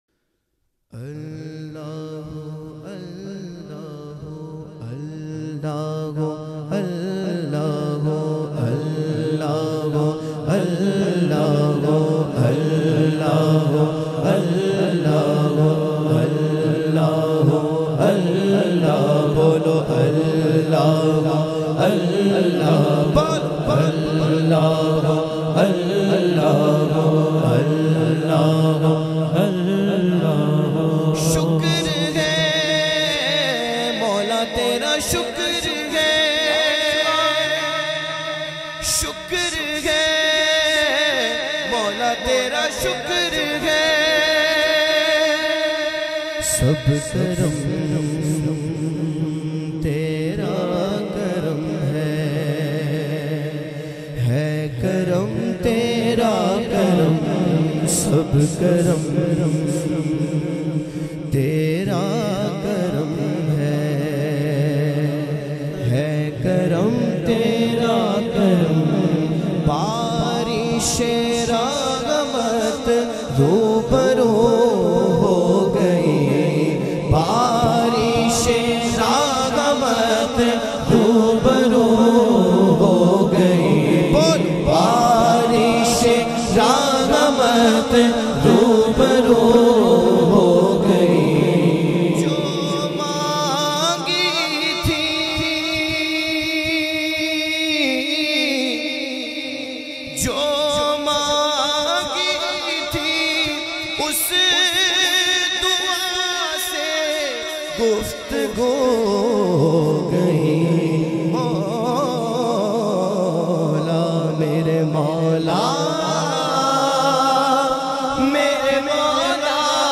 Hamd e Bari Tala